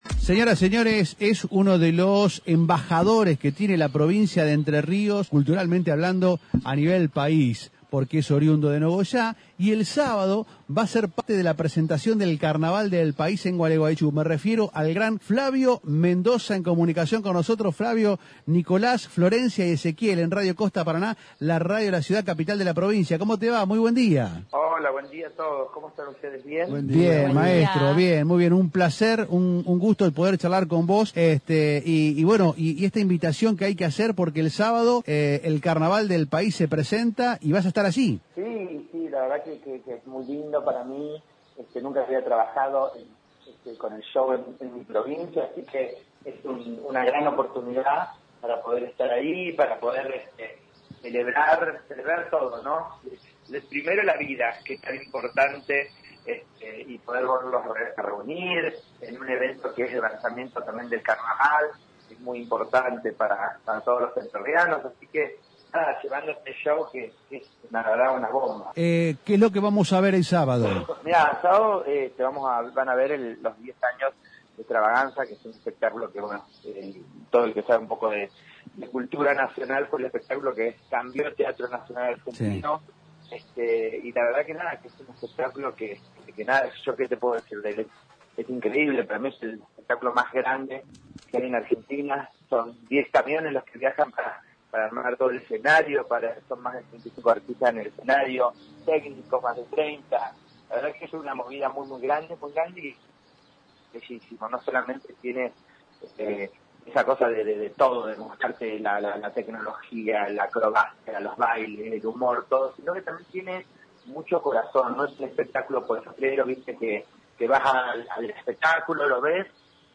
Estamos llevando un show que es una bomba”, anticipó el artista en diálogo con radio “Costa Paraná”.